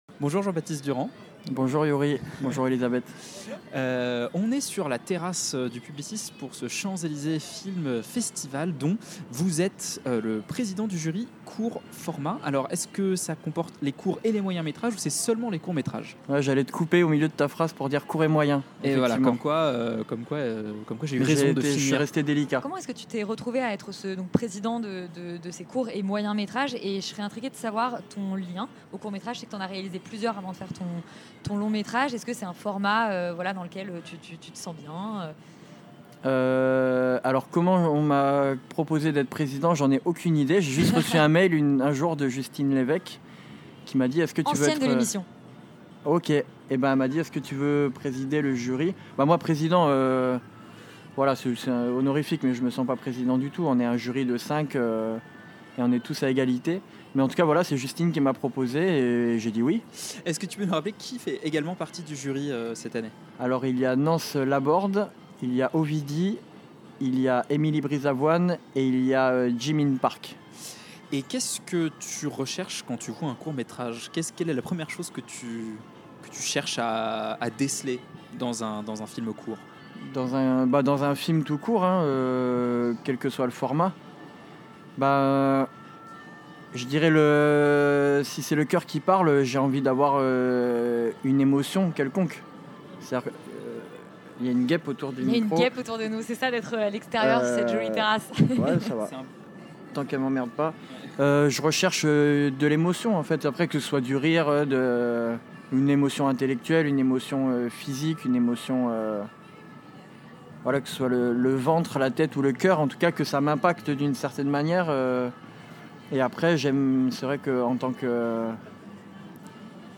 Extérieur Nuit : interview